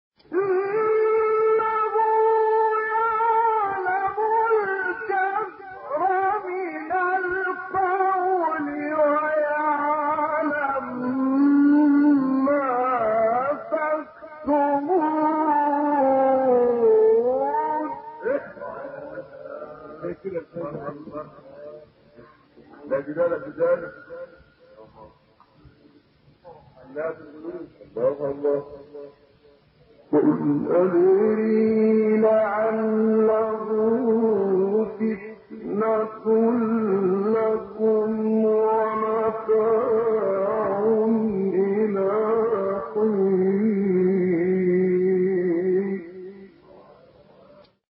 گروه فعالیت‌های قرآنی: فرازهای صوتی از قراء برجسته جهان اسلام را می‌شنوید.